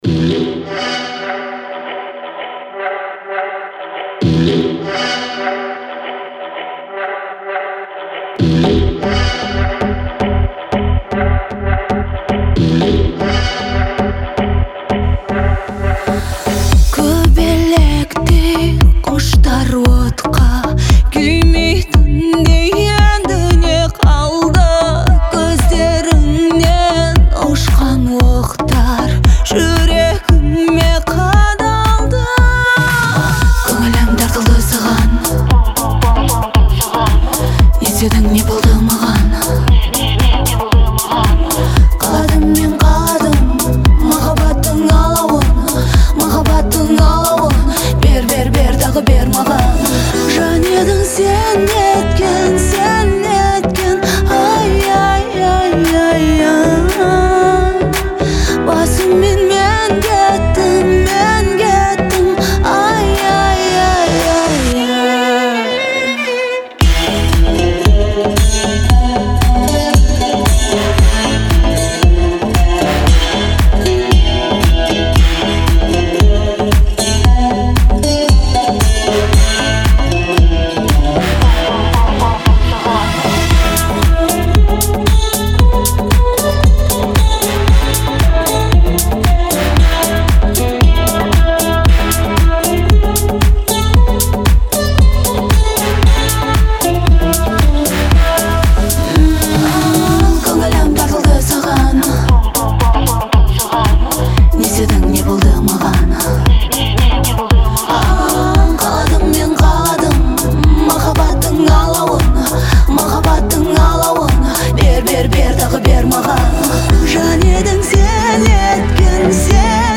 исполняет трек с мощным вокалом, передавая всю гамму эмоций.